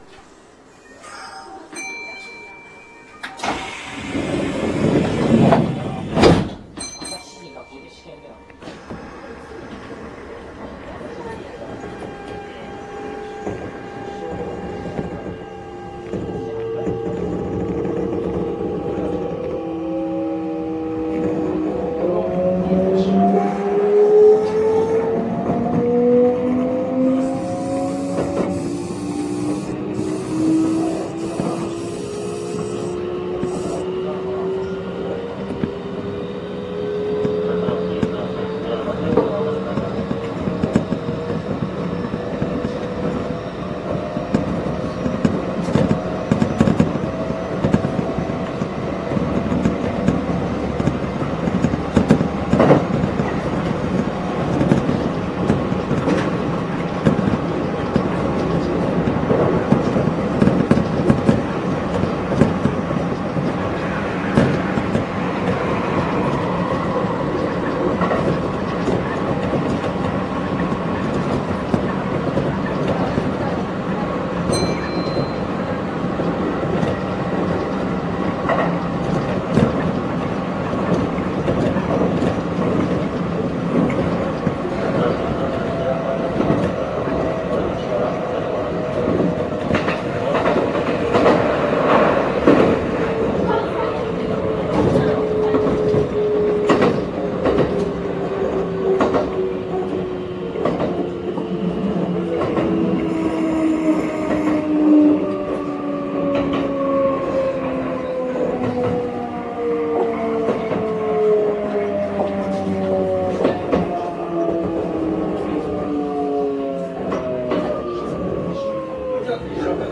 各系列では装備品、固定編成両数、走行線区などで細かく形式を分けていて、全形式録るのは大変です…。１２４９系以前では東急９０００系と同じ音でしたが現在はソフト変更されて、他では聞けない近鉄独特の音になっています。
走行音（1244）
収録区間：奈良線 布施→今里